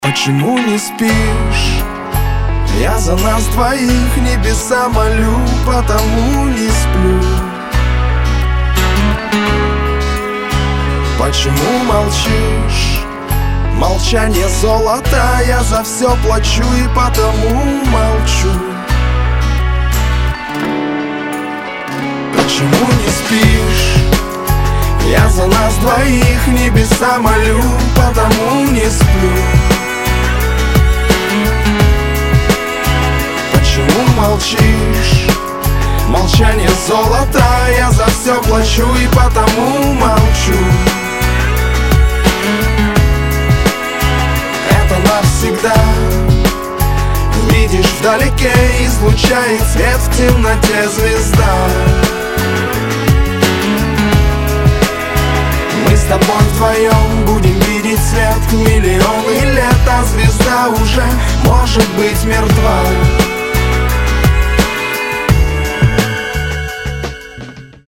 романтичные
Очень романтичная композиция